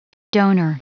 Prononciation du mot donor en anglais (fichier audio)
Prononciation du mot : donor